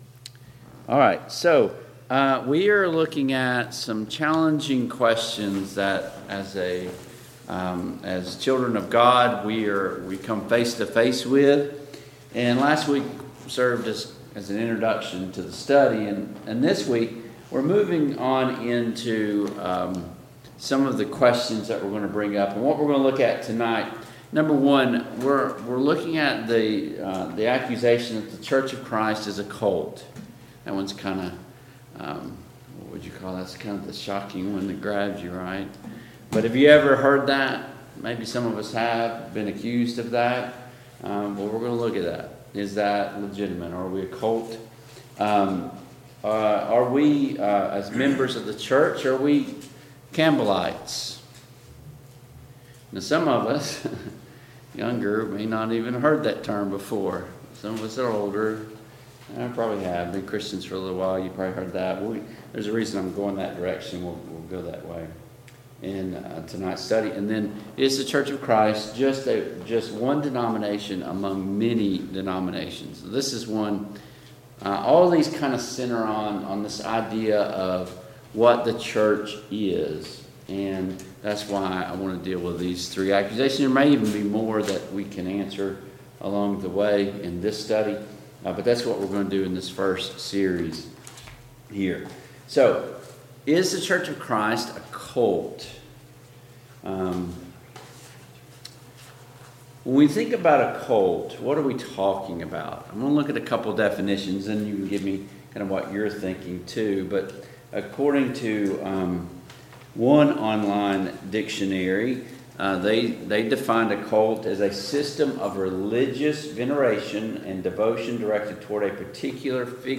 Service Type: Mid-Week Bible Study Download Files Notes Topics: Defending the Faith , The Church « What is the Work of the Holy Spirit today? 7.